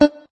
note_beepey_2.ogg